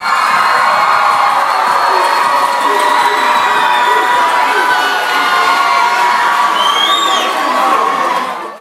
Category 🗣 Voices
meme meme-adjacent meme-related profanity scream screaming yell yelling sound effect free sound royalty free Voices